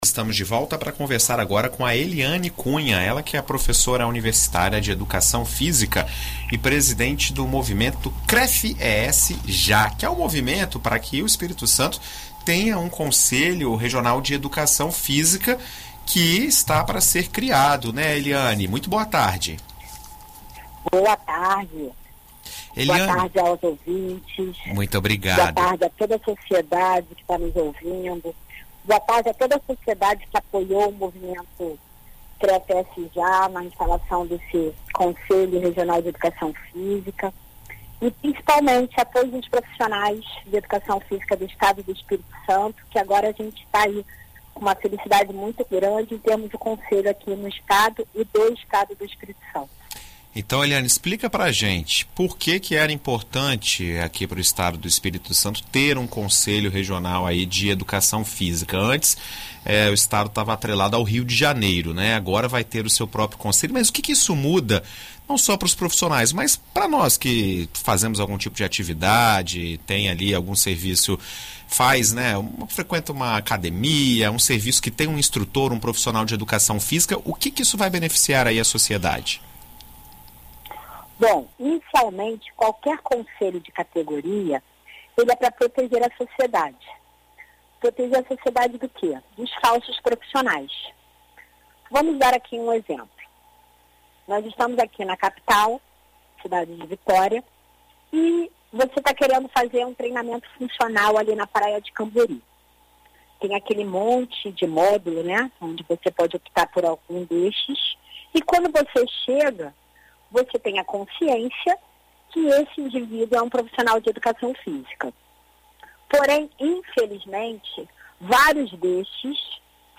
O Espírito Santo agora passa a contar com um Conselho Regional de Educação Física, antes pertencente ao Rio de Janeiro. Em entrevista à BandNews FM Espírito Santo nesta quarta-feira